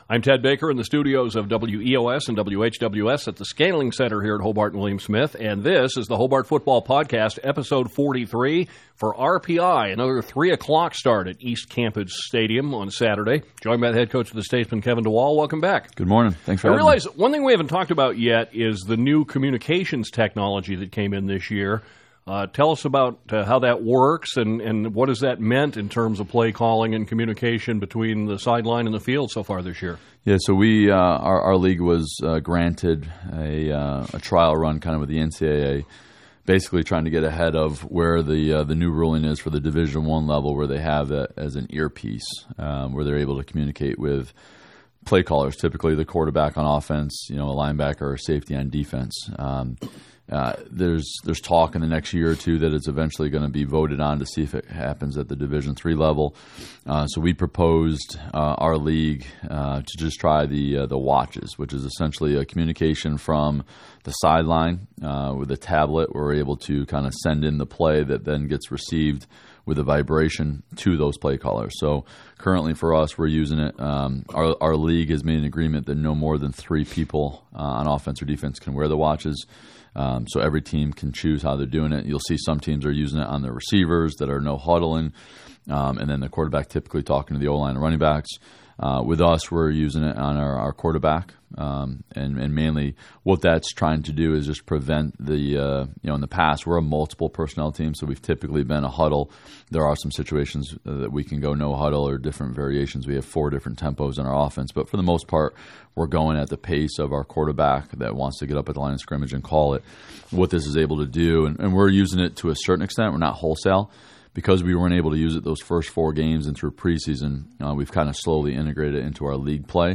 Back in the studio